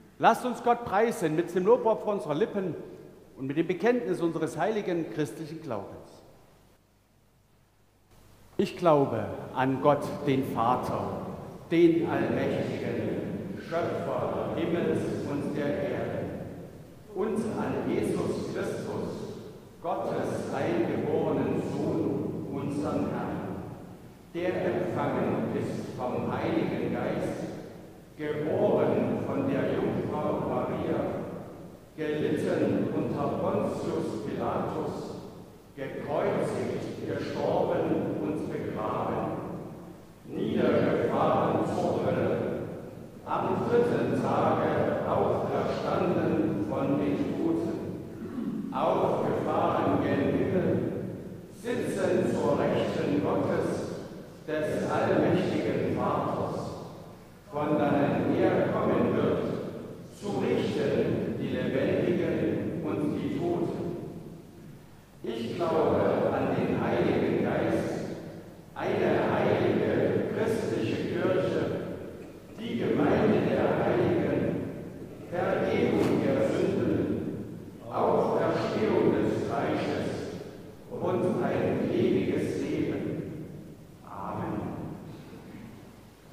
Apostolisches Glaubensbekenntnis Ev.-Luth.
Audiomitschnitt unseres Gottesdienstes vom 1.Avent 2024